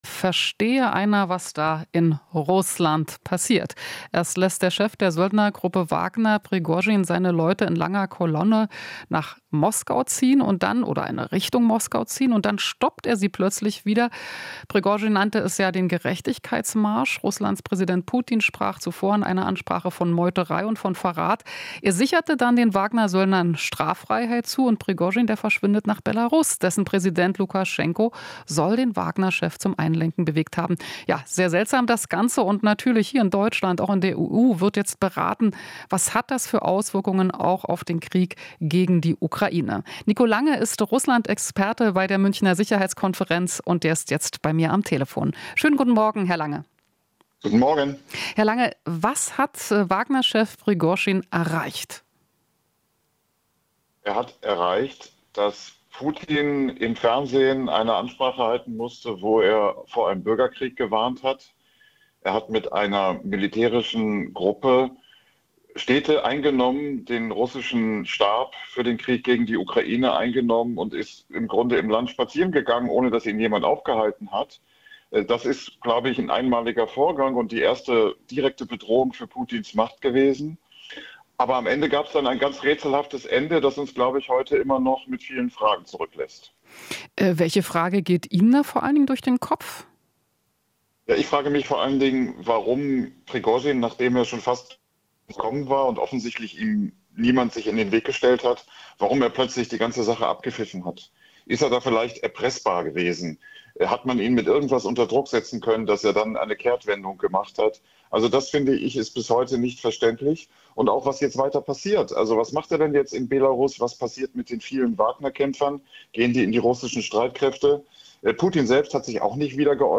Interview - Wagner-Aufstand: "Erste direkte Bedrohung für Putins Macht"